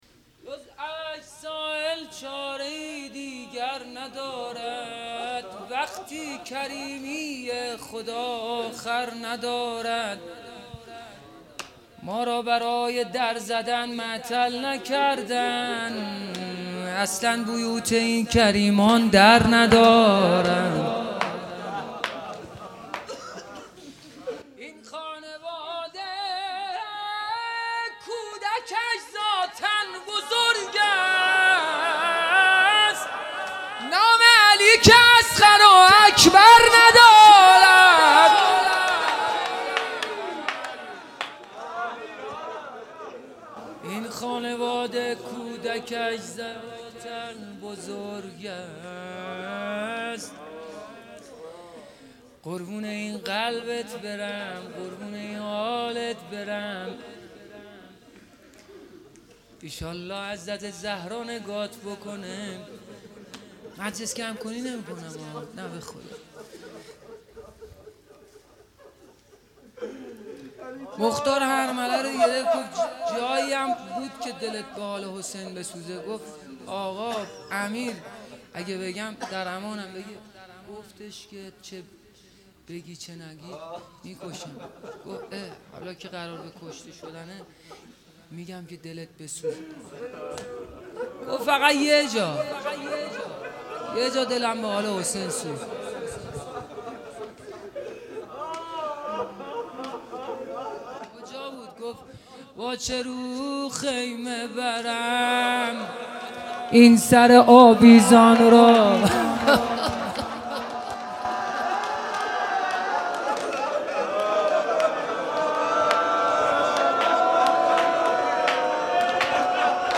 روضه و شور
شب هفتم ماه مبارک رمضان